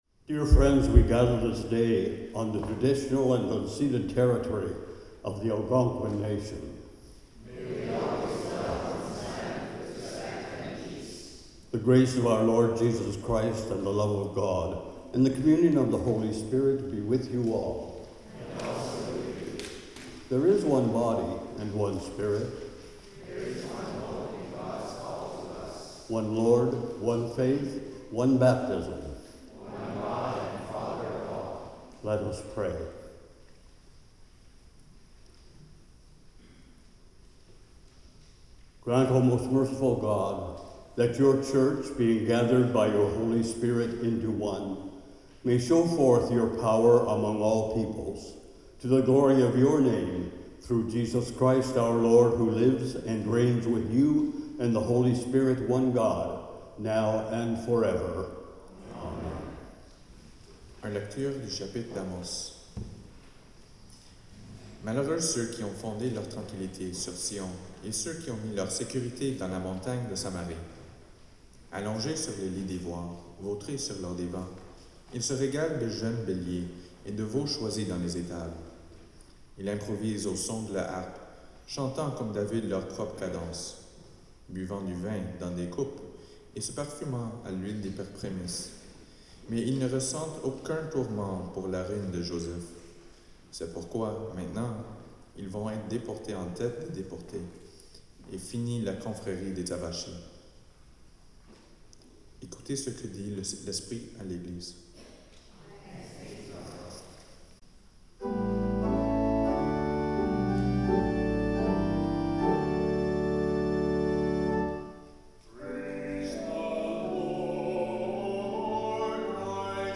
NATIONAL DAY FOR TRUTH & RECONCILIATIONSIXTEENTH SUNDAY AFTER PENTECOST
*This abridged recording omits the Baptism and Eucharist.